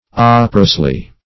-- Op"er*ose`ly , adv.
operosely.mp3